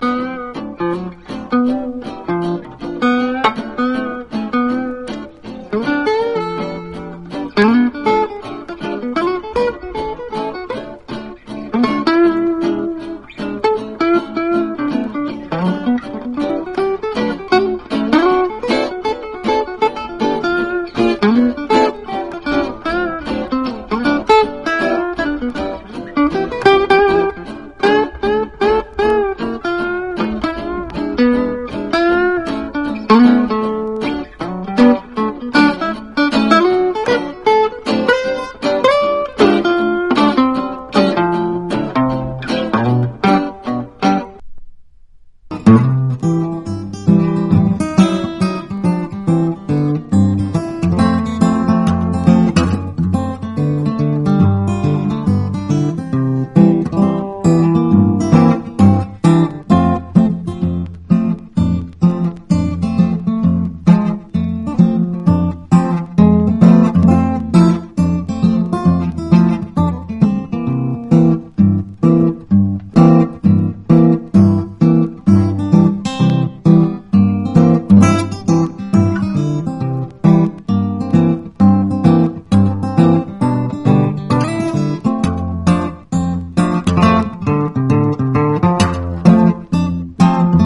パワーポップ/ウェイヴィー・ポップ好きにもオススメの80年美メロA.O.R.！